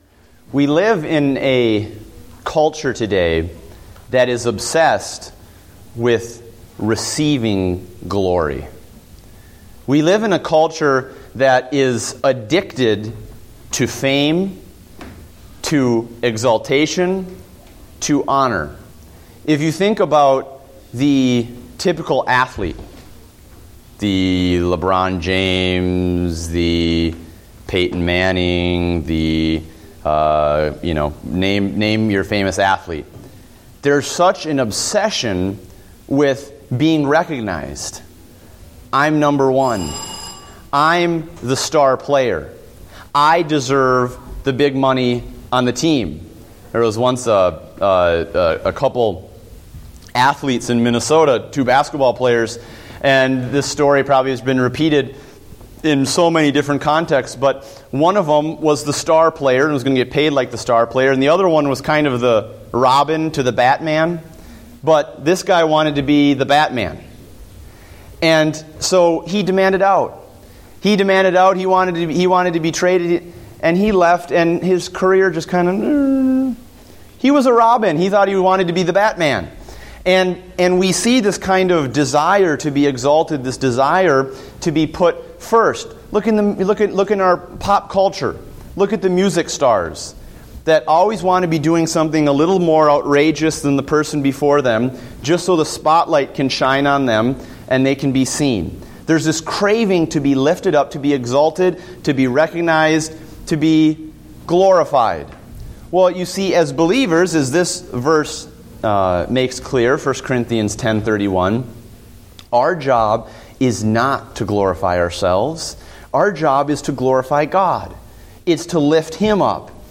Date: August 31, 2014 (Adult Sunday School)